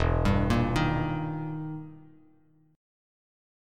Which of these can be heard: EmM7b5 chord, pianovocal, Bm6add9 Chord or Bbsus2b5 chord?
EmM7b5 chord